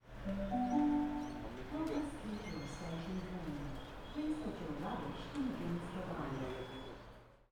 announcement3.ogg